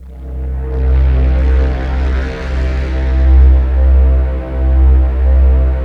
ALPS C2.wav